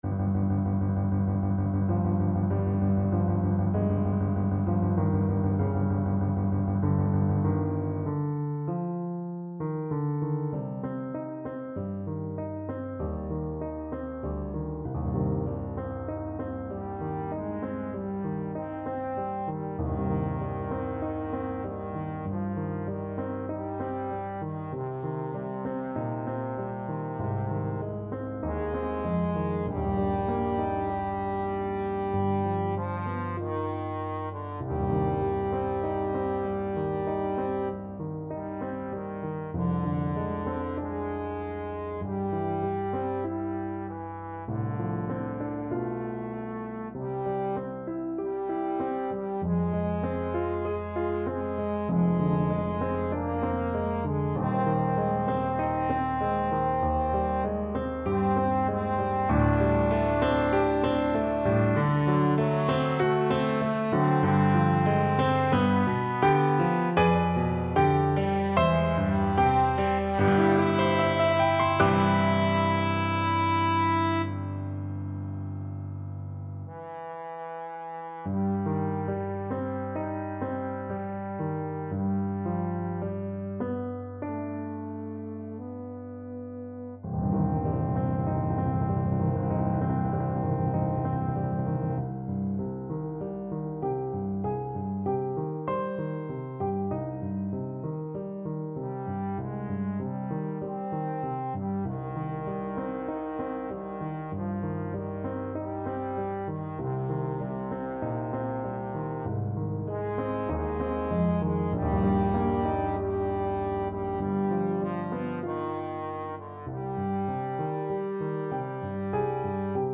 Trombone version
Classical Trombone
Piano Playalong MP3